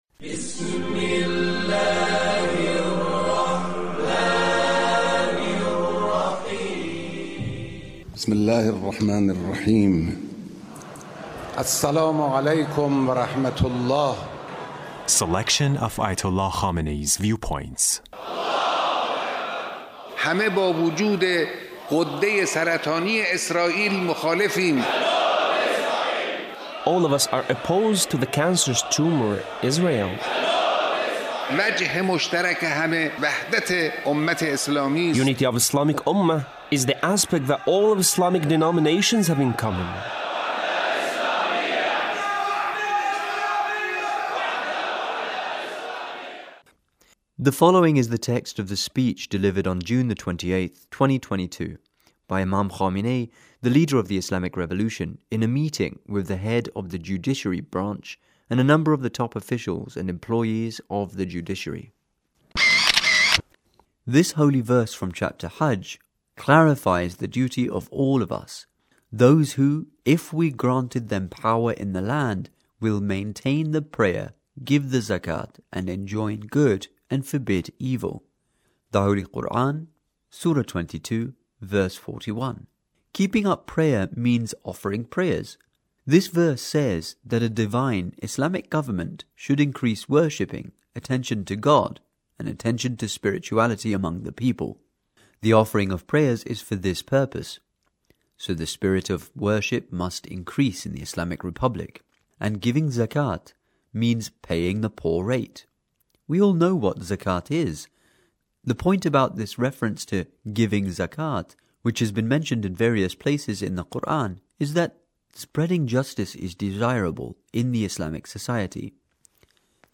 The Leader's speech in a meeting with a number of the top officials and employees of the Judiciary.